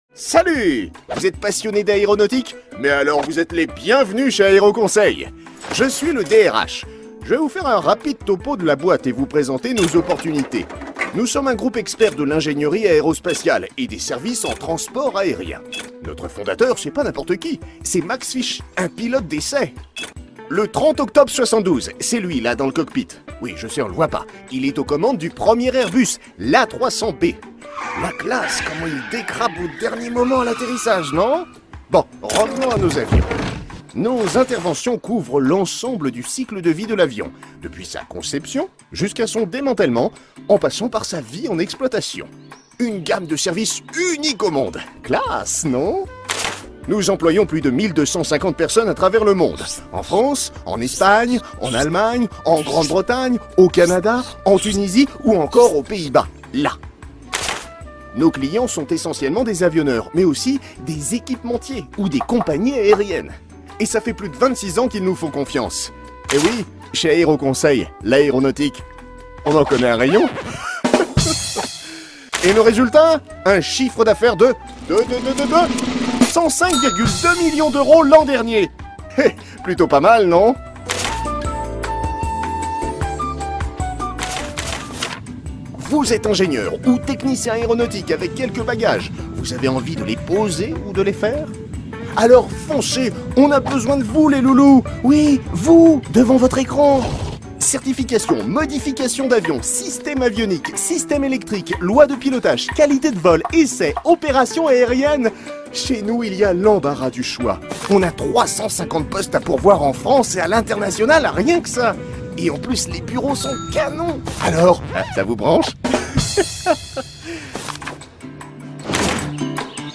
Prestation voix-off pour Aéroconseil : sérieux, fou, drôle et complice
Recruteur un peu azimuté.
Film corporate pour Aeroconseil.
Pour Aeroconseil, j’ai incarné un personnage de recruteur un peu azimuté, avec une voix médium grave. Jouant sur des tons fous, drôles, amusants, dynamiques et complices, j’ai su donner une tonalité unique et distincte à leur message institutionnel.
Pour Aeroconseil, cela se traduisait par une interprétation légèrement décalée du recruteur, ajoutant une note d’humour et de complicité qui a rendu la publicité mémorable et engageante.